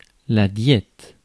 Ääntäminen
Synonyymit régime Ääntäminen France Tuntematon aksentti: IPA: /djɛt/ Haettu sana löytyi näillä lähdekielillä: ranska Käännös Konteksti Ääninäyte Substantiivit 1. diet lääketiede US Suku: f .